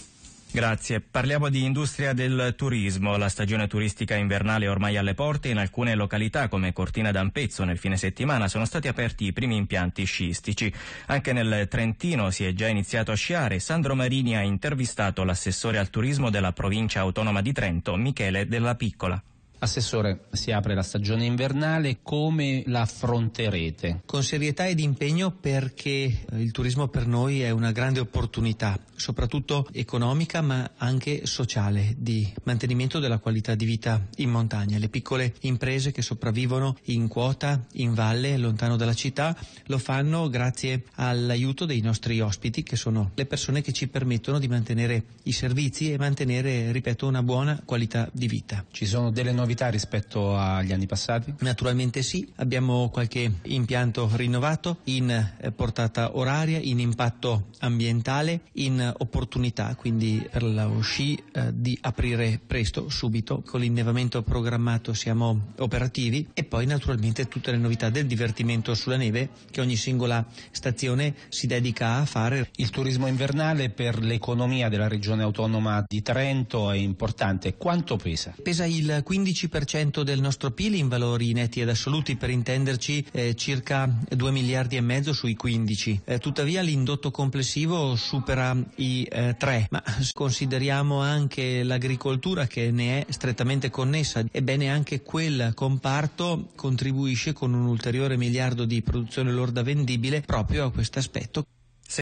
intervista-a-RADIO-1-NEWS-ECONOMY1.mp3